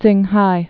(tsĭnghī)